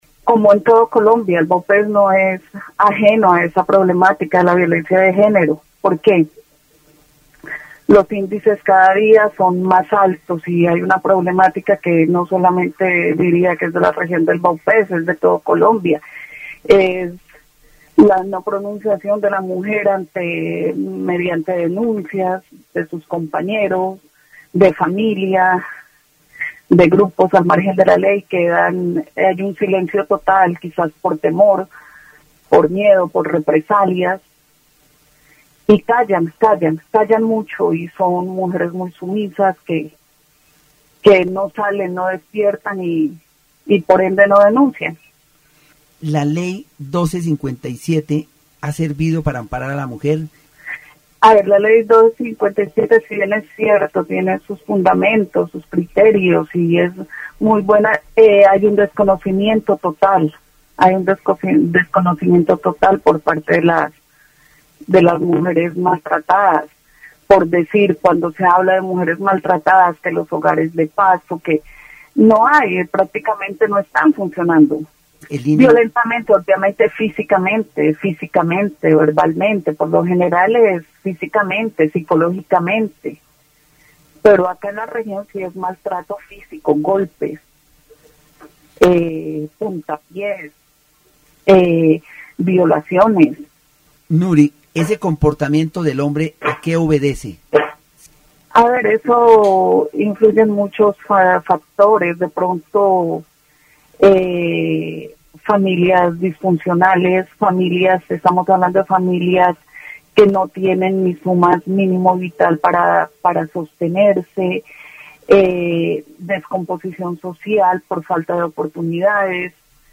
Programas de radio
Entrevistas